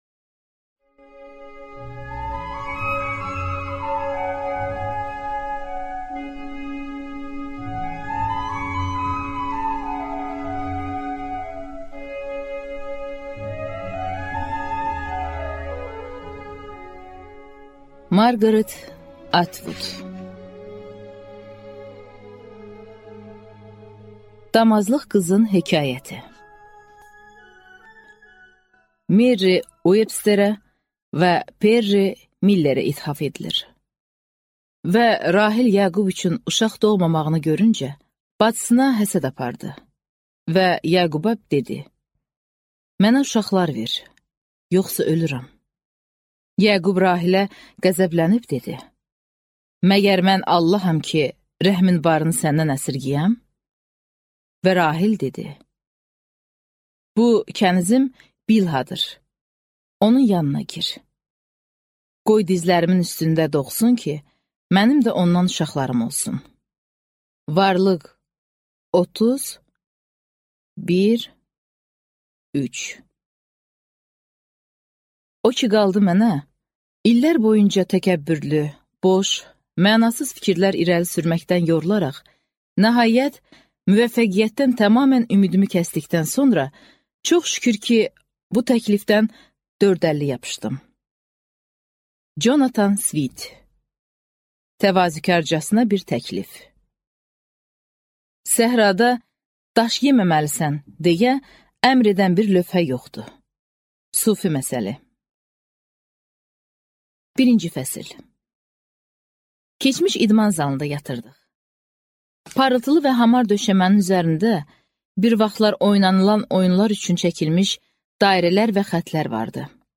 Аудиокнига Damazlıq qızın hekayəti | Библиотека аудиокниг